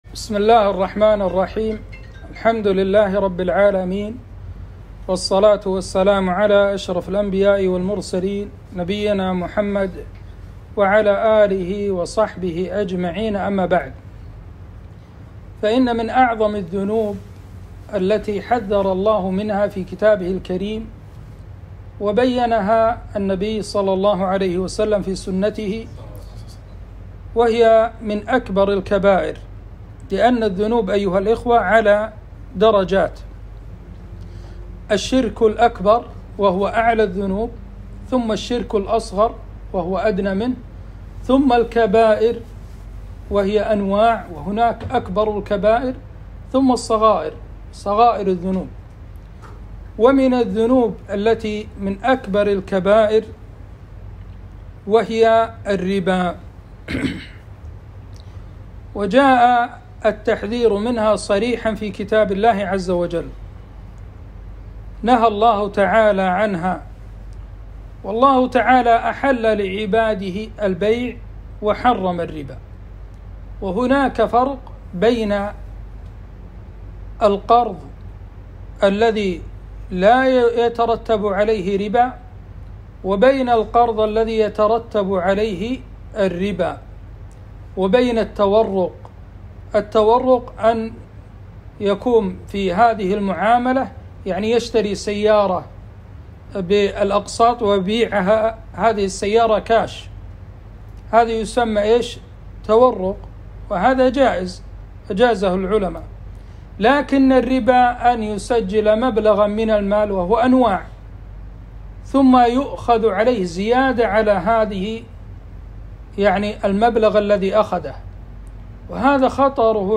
محاضرة - تحريم الربا وبيان خطره